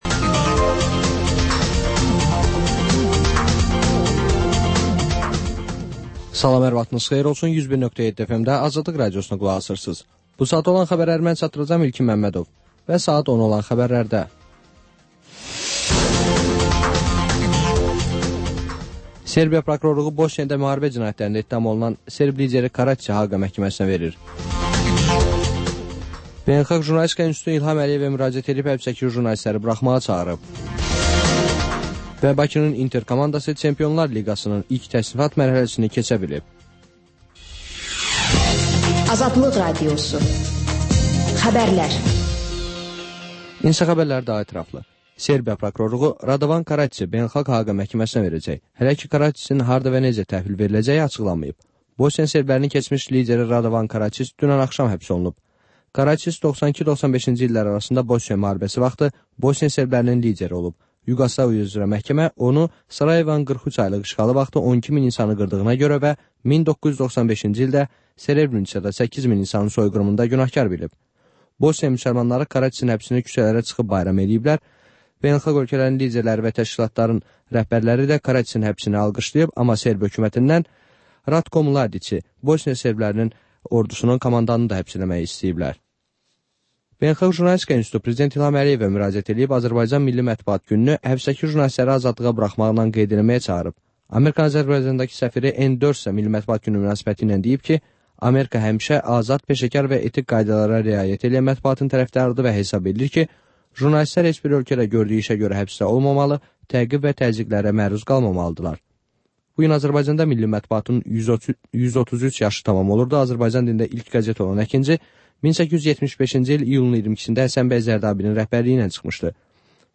Xəbərlər, müsahibələr, hadisələrin müzakirəsi, təhlillər